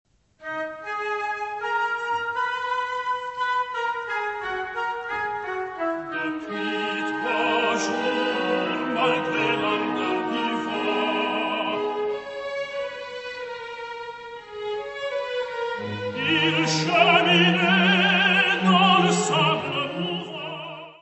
: stereo; 12 cm
Music Category/Genre:  Classical Music
Allegro non troppo (Récitant): Depuis trois jours,...
Joseph, Choeurs de romains et d'Egyptiens.